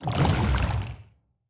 Sfx_creature_bruteshark_swim_slow_06.ogg